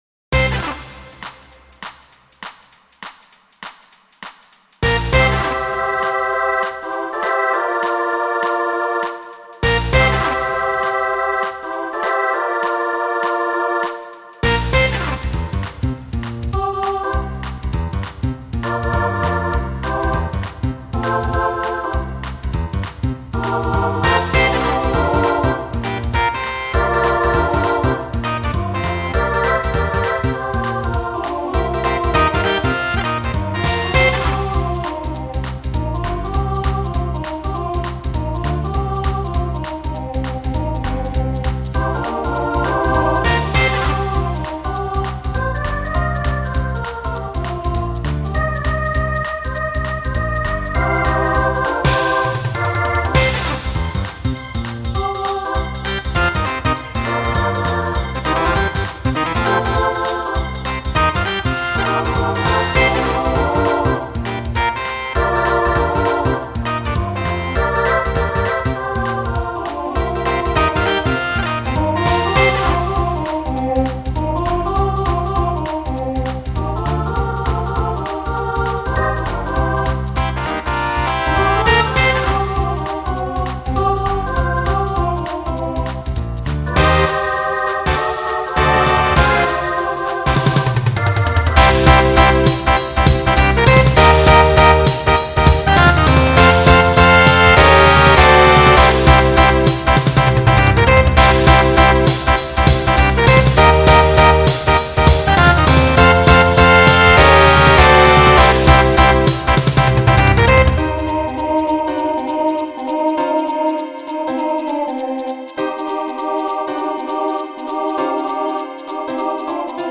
New TTB voicing for 2020